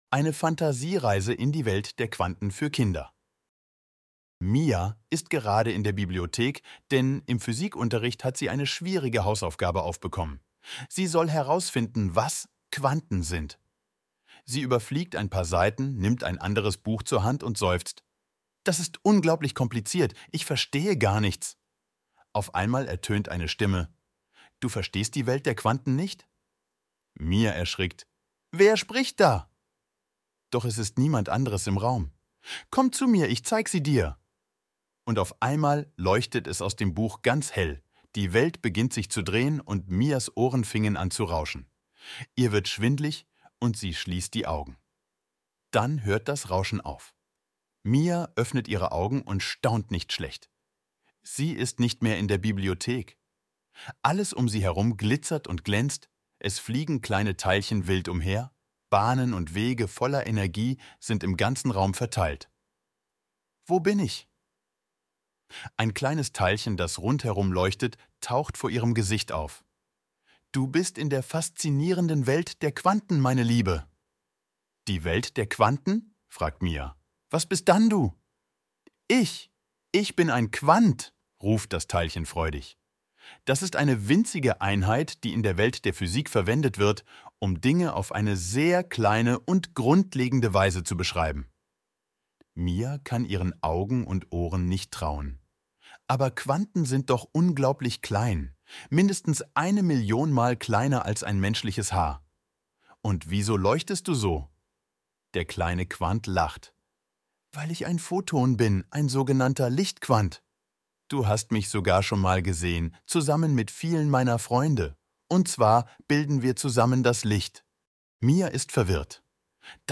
• Hier kann man sich die Fantasiereise vorlesen lassen (KI-generierte Audio):